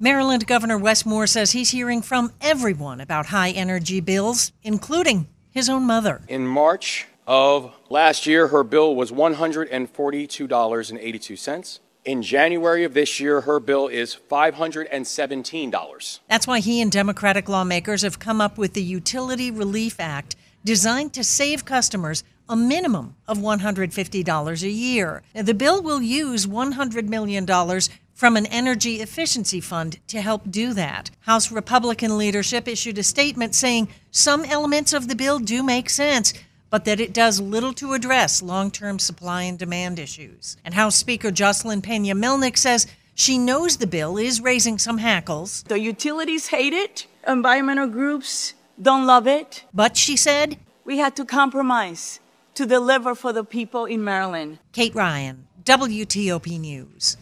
Live Radio